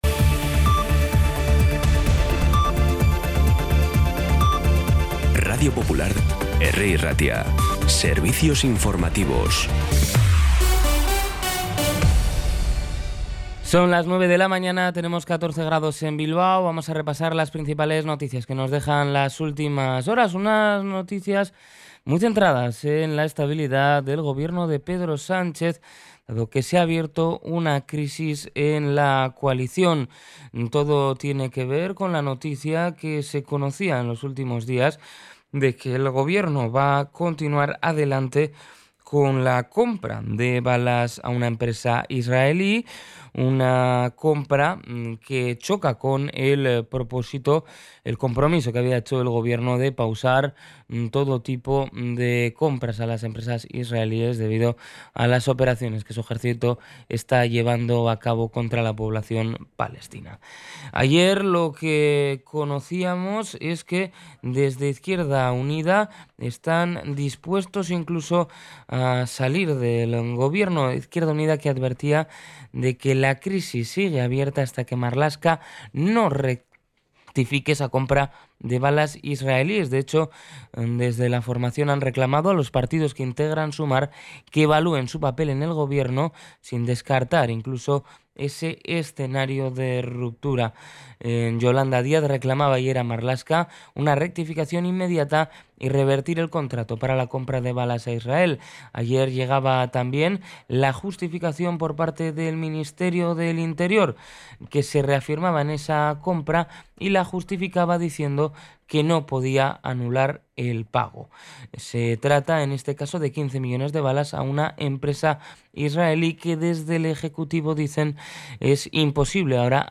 Las noticias de Bilbao y Bizkaia del 24 de abril a las 9
Los titulares actualizados con las voces del día.